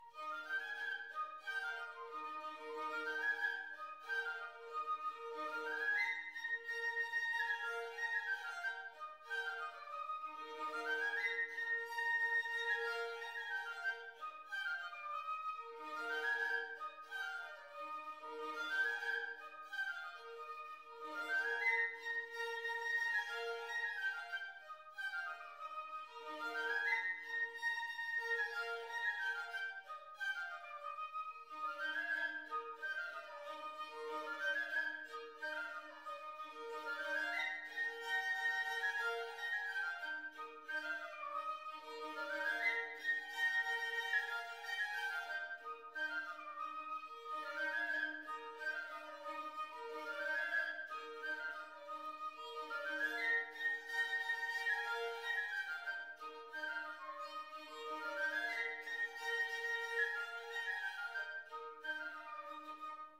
Musique : rigaudon de Veynes - 0,788 Mo - 1 mn 03 :
rigaudon.mp3